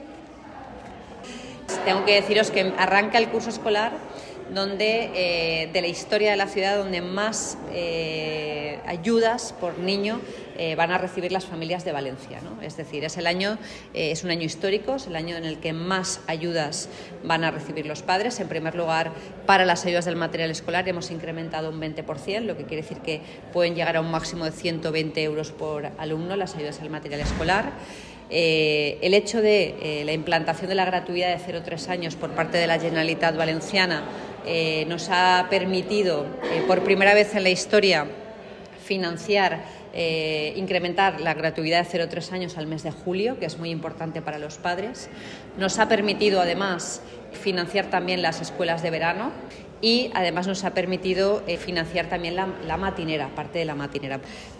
• La alcaldesa, durante la visita en la Escuela Infantil Municipal Solc este lunes